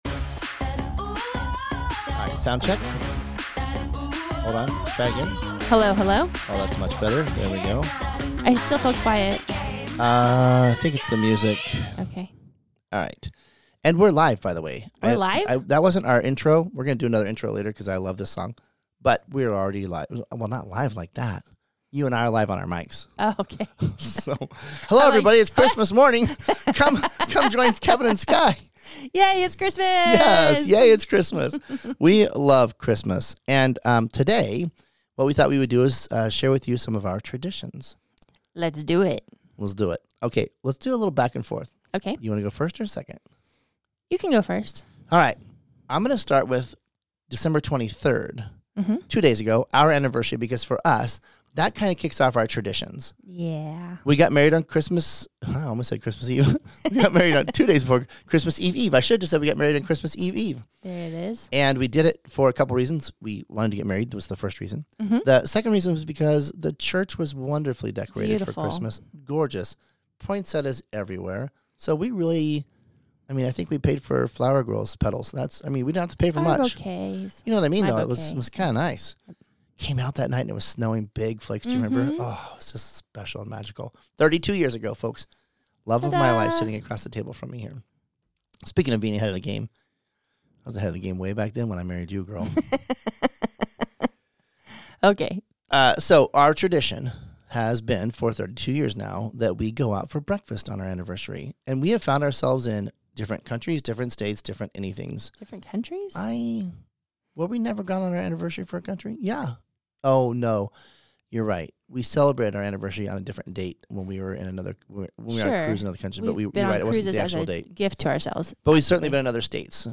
In Sweet Lobs Episode Thirty-One, we take a step back to enjoy a shorter, more relaxed conversation about the holidays. We share some of our favorite holiday traditions and dive into a few pickleball traditions that are newer to us.